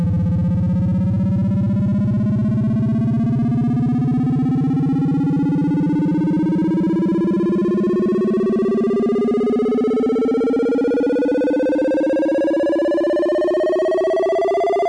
boost_sfx.wav